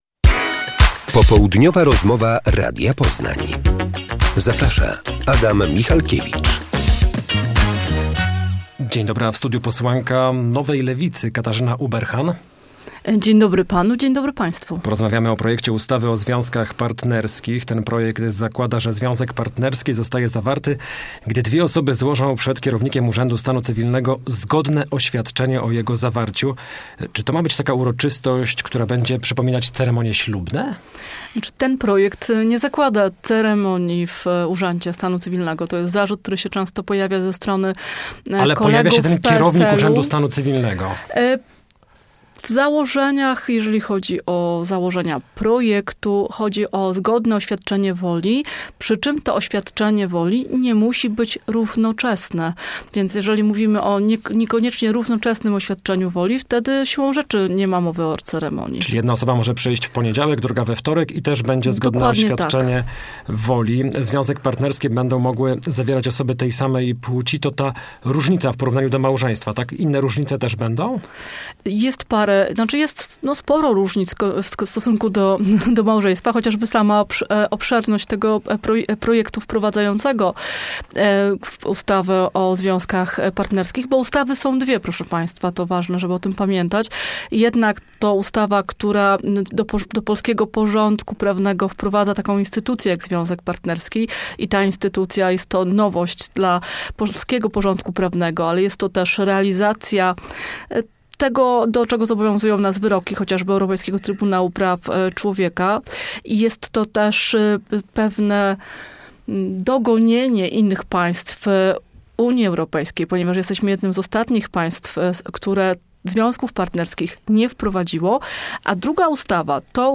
Popołudniowa rozmowa Radia Poznań - Katarzyna Ueberhan
Co zakłada projekt ustawy o związkach partnerskich? Gościem jest Katarzyna Ueberhan z Nowej Lewicy.